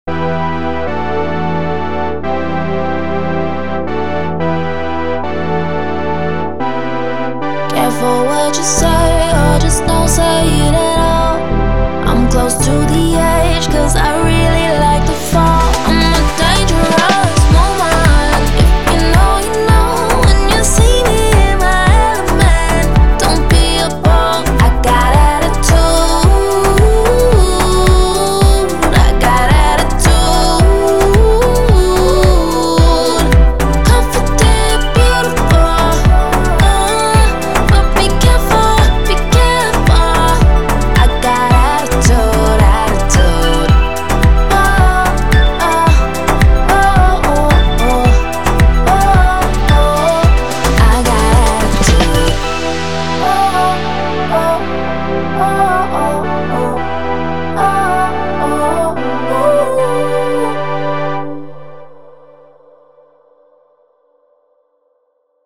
Pop, Dance
E Minor